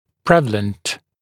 [‘prevələnt][‘прэвэлэнт]широко распространенный, преобладающий